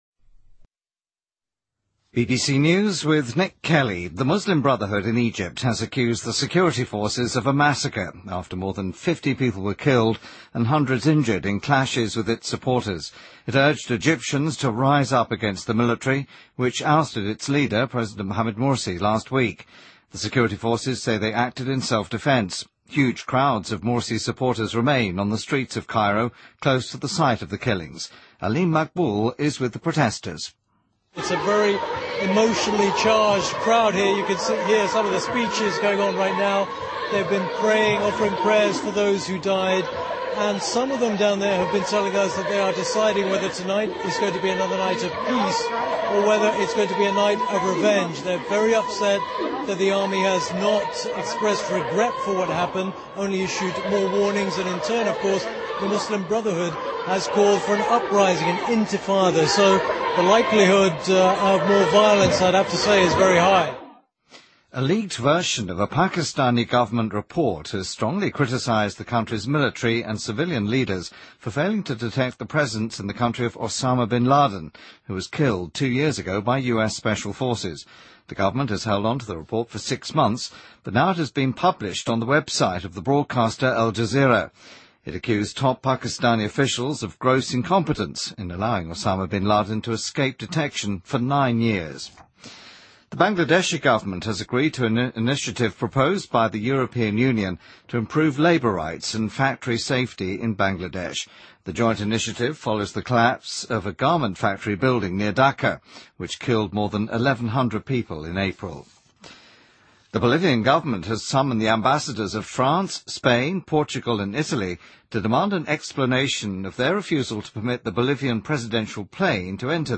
BBC news,比利时不孕不育医生称开发出简单试管受精